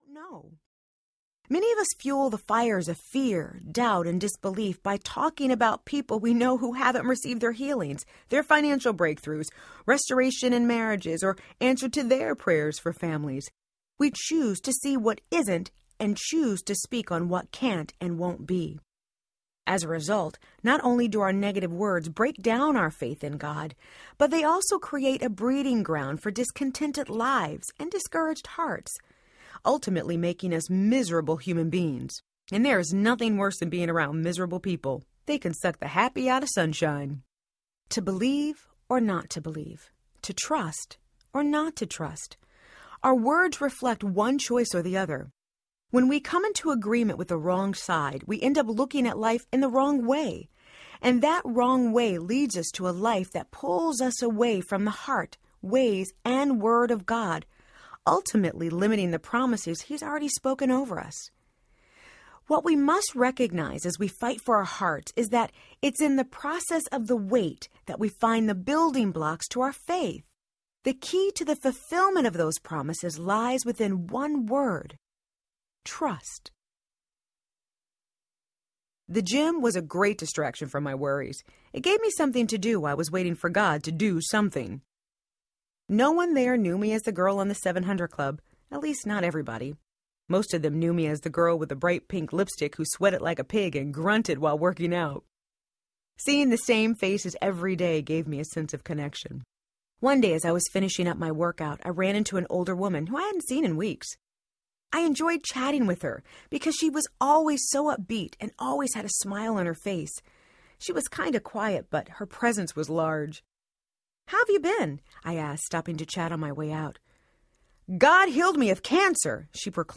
Talk Yourself Happy Audiobook
5 Hrs. – Unabridged